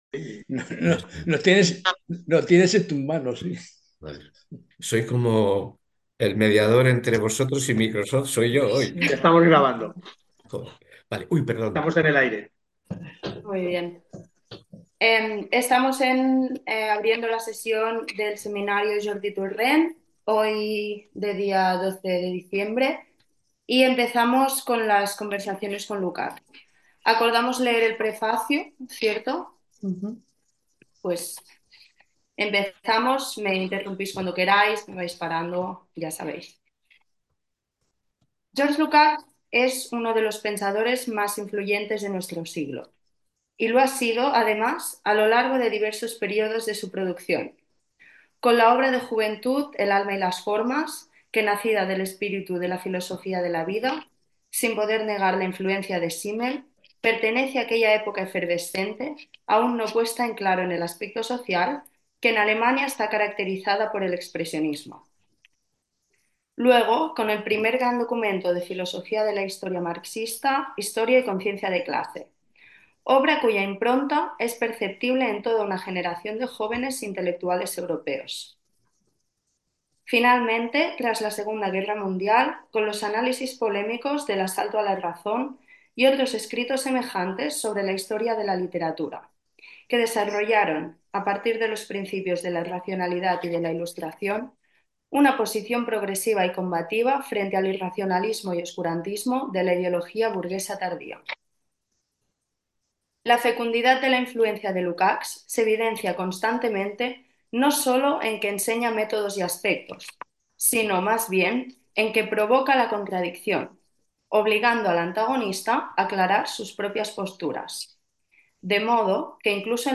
La forma de proceder es leer anticipadamente unas 20 o 25 páginas de texto, que posteriormente son releídas y comentadas en una puesta en común, que dirige un monitor.
En cualquier caso, la grabación del seminario será publicada posteriormente en la web de Espaimarx.